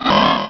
Cri de Skelénox dans Pokémon Rubis et Saphir.